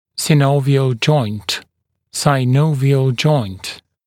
[sɪˈnəuvɪəl ʤɔɪnt] [sʌɪ-][сиˈноувиэл джойнт] [сай-]синовиальный сустав